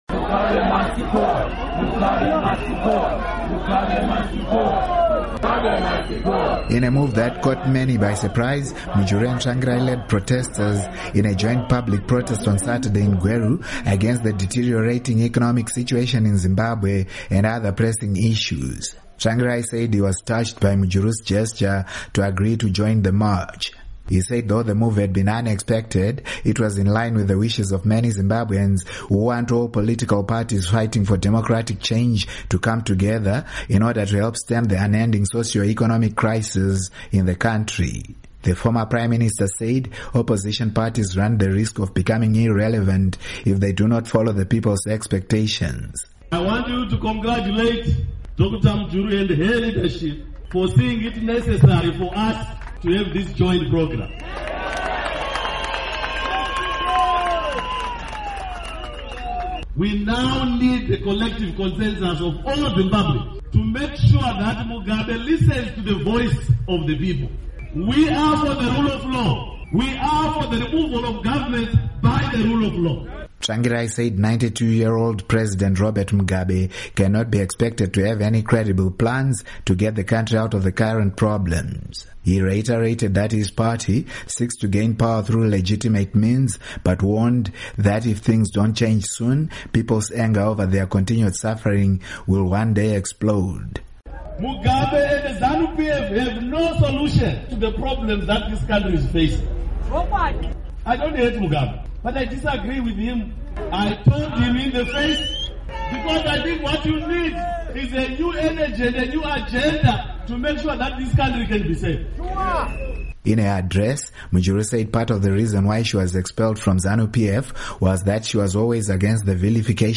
Report On MDC - Mujuru